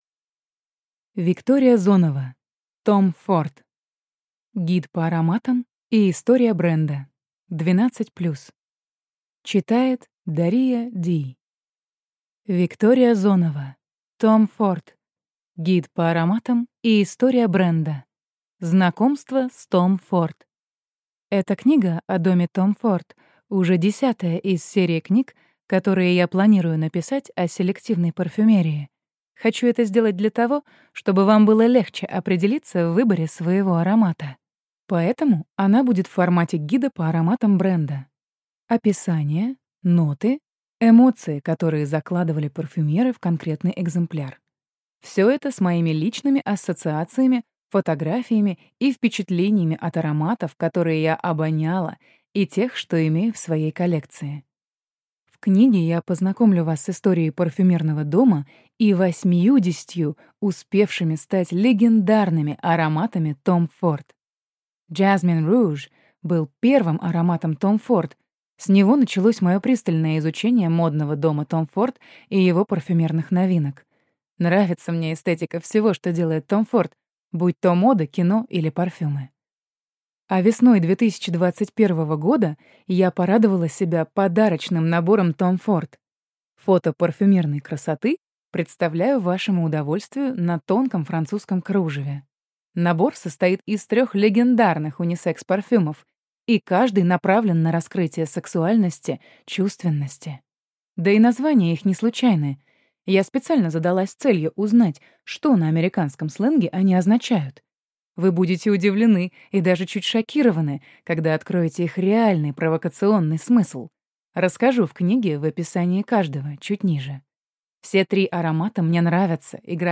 Аудиокнига Tom Ford. История бренда и гид по ароматам | Библиотека аудиокниг